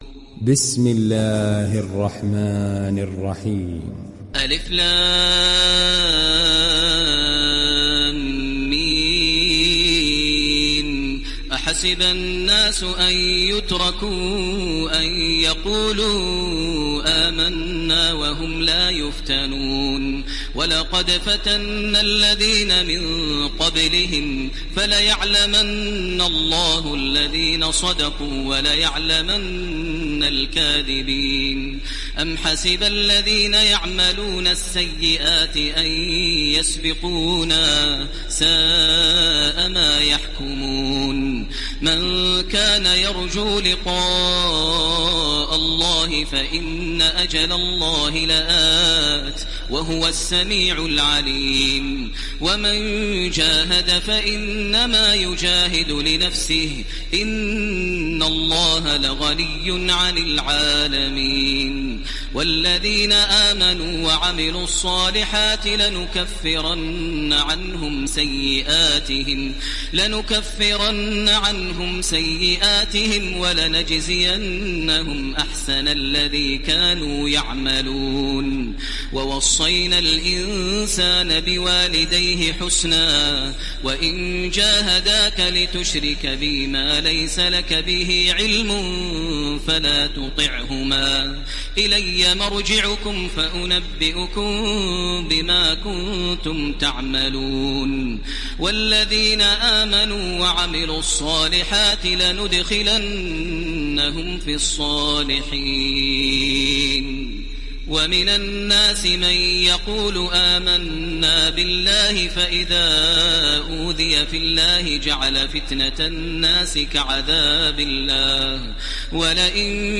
ডাউনলোড সূরা আল-‘আনকাবূত Taraweeh Makkah 1430